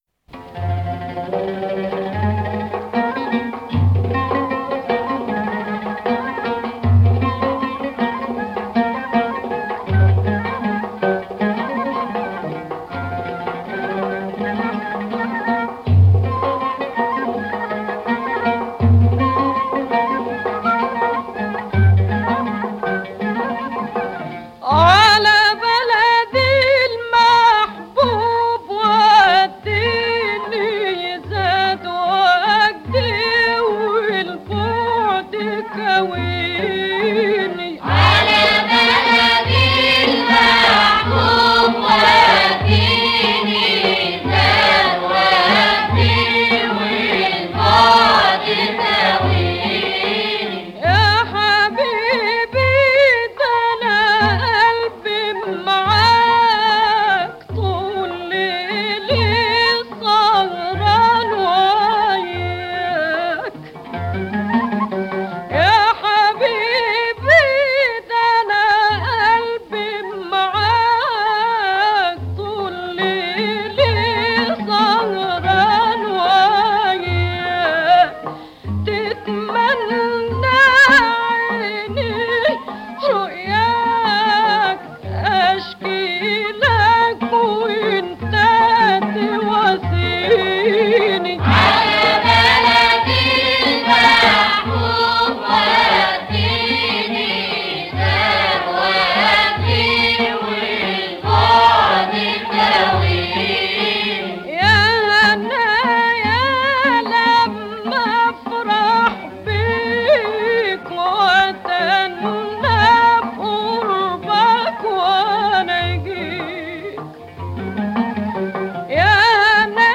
ترانه عربی اغنية عربية